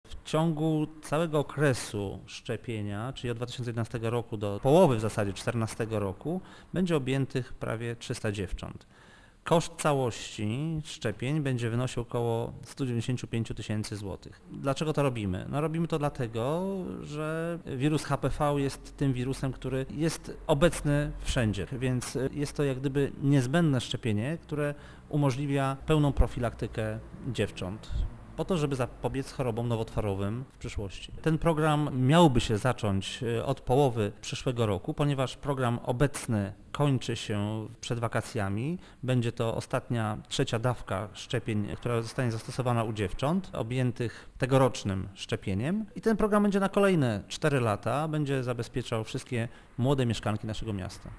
- Nie bez znaczenia jest też duże zainteresowanie tą ofertą wśród dziewcząt w wieku 12 i 13 lat - mówi Jerzy Wrzesień: